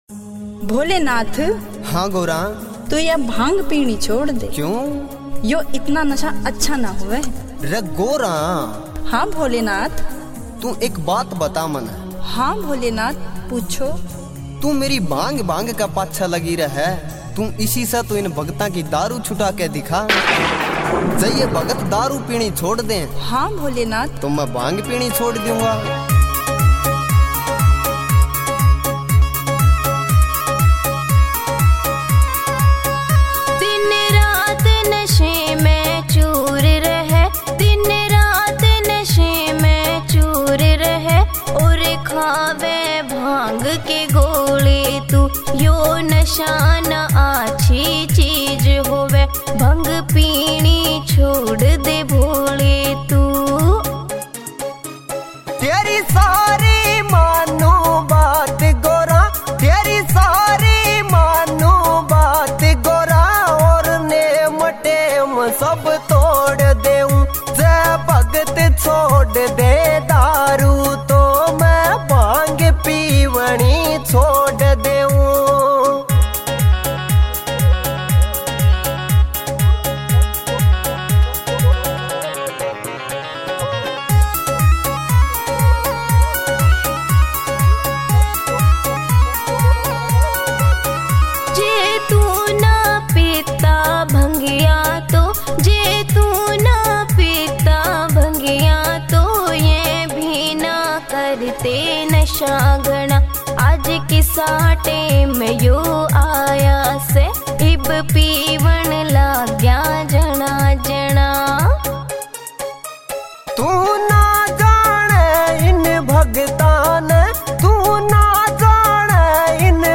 Bhakti Songs
» Haryanvi Songs